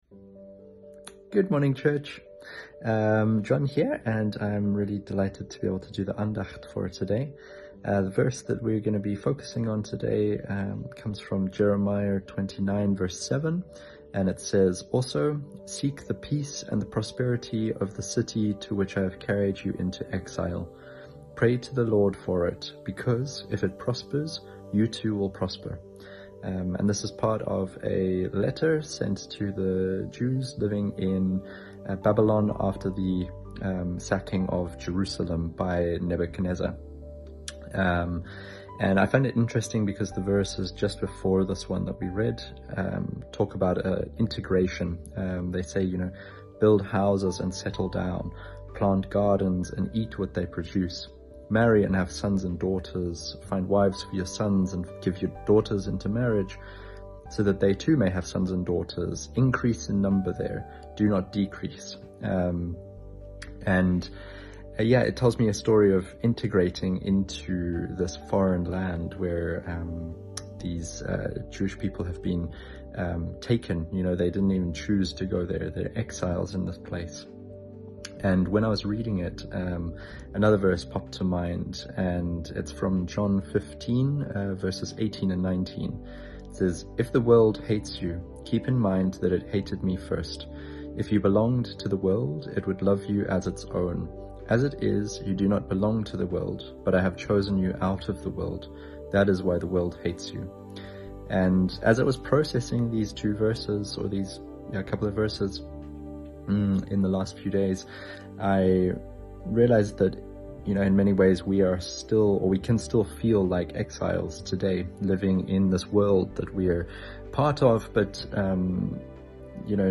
Tag 17 der Andacht zu unseren 21 Tagen Fasten & Gebet